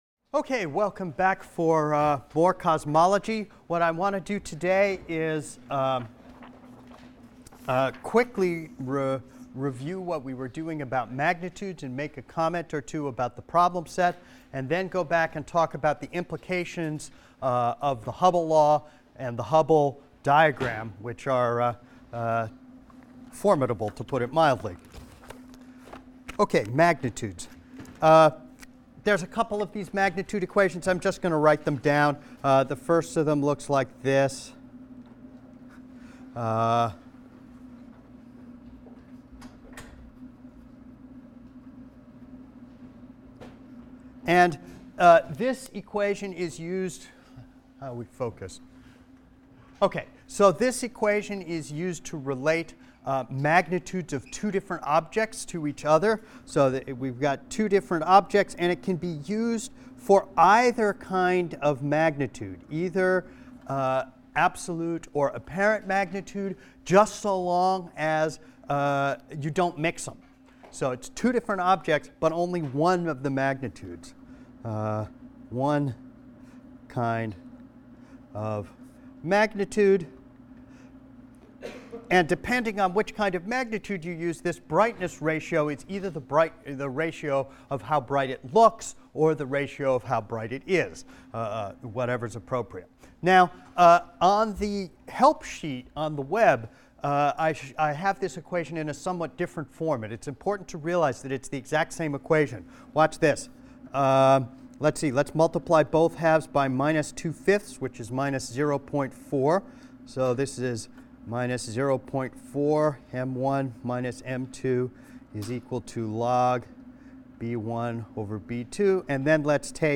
ASTR 160 - Lecture 17 - Hubble’s Law and the Big Bang (cont.) | Open Yale Courses